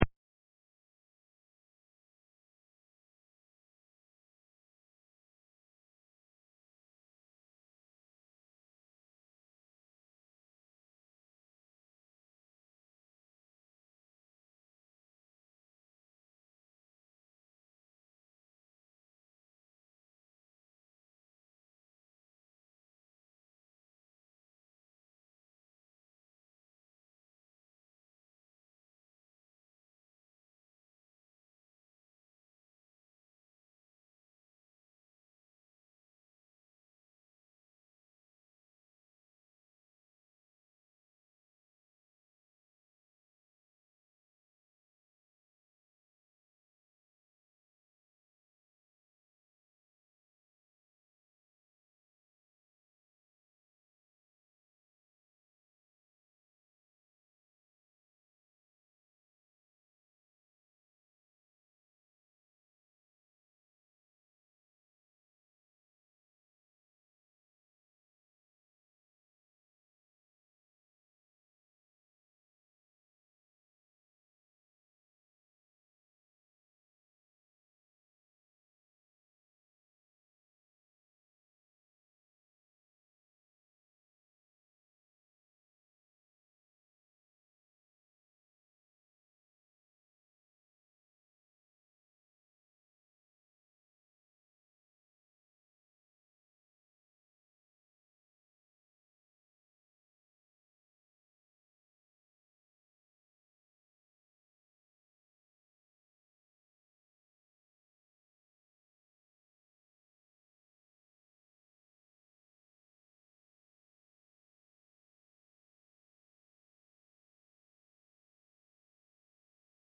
2019 Service Type: Sunday Service Preacher